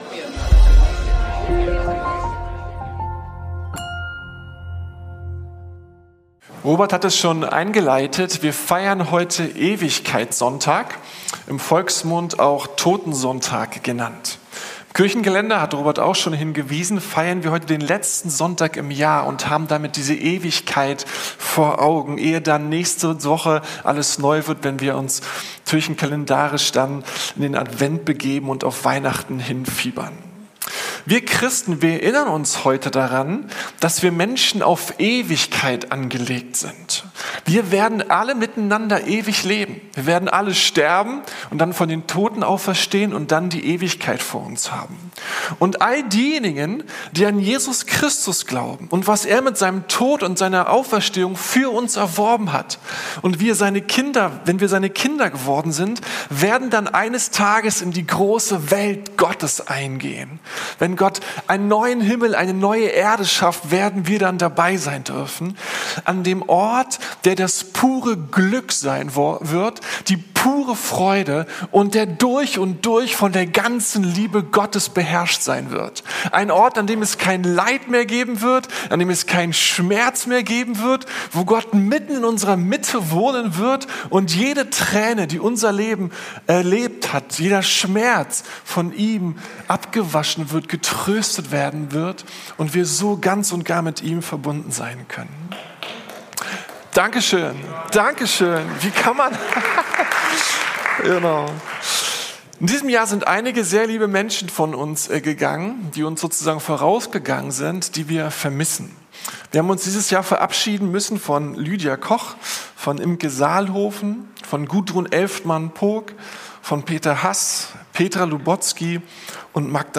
Kolosserbrief - Herr aller Mächte ~ Predigten der LUKAS GEMEINDE Podcast